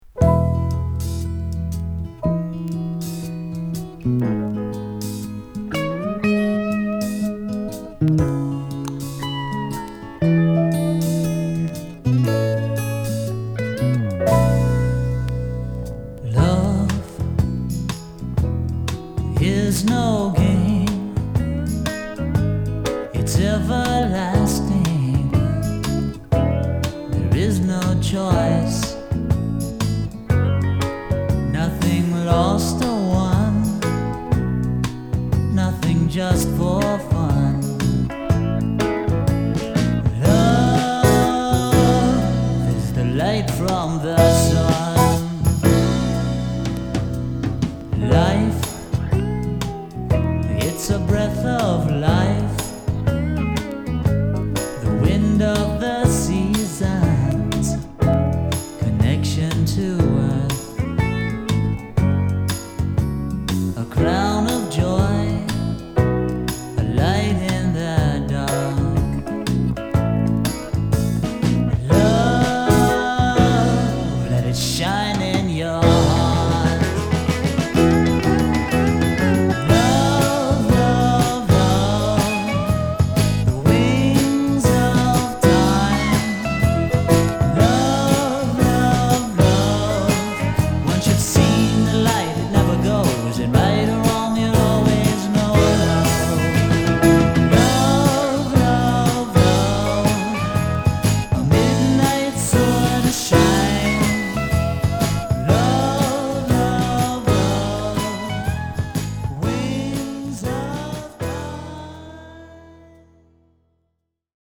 70's Folk Rock!!